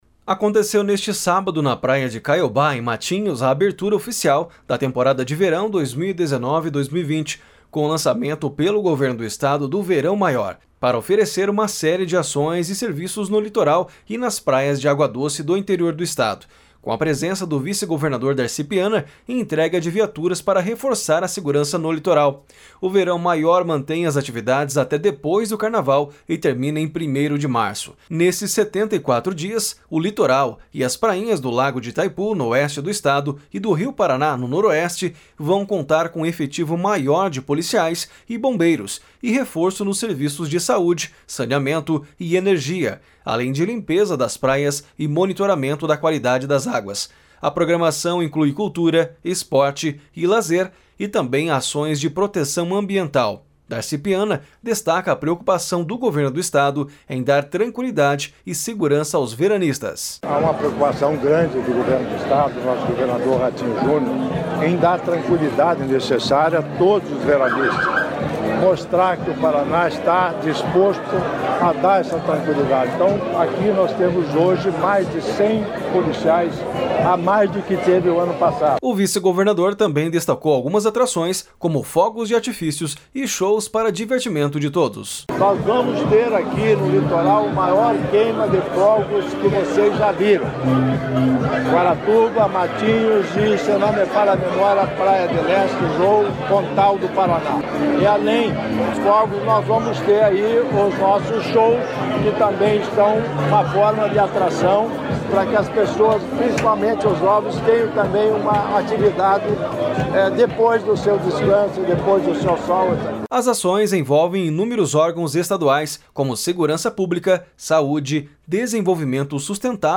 Aconteceu neste sábado na praia de Caiobá, em Matinhos, a abertura oficial da temporada de Verão 2019/2020, com o lançamento pelo Governo do Estado do Verão Maior, para oferecer uma série de ações e serviços no Litoral e nas praias de água doce do Interior do Estado.
Darci Piana, destaca a preocupação do Governo do Estado em dar tranquilidade e segurança aos veranistas.